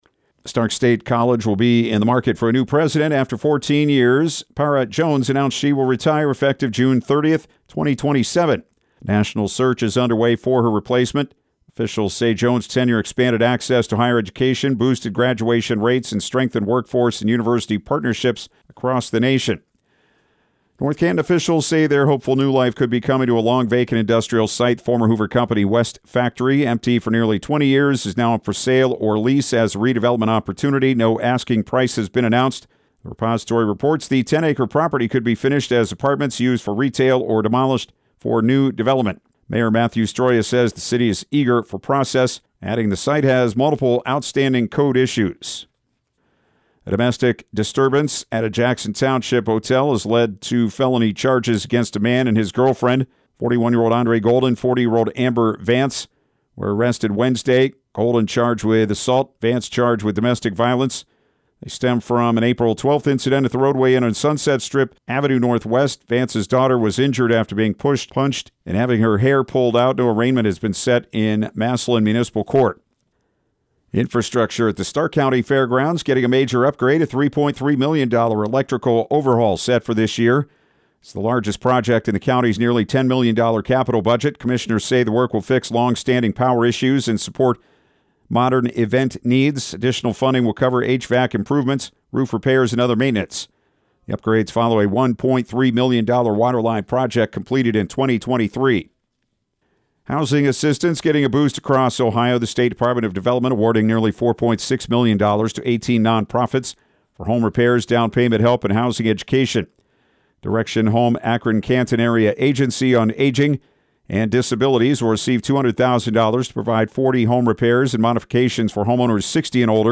Evening News
EVENING-NEWS-23.mp3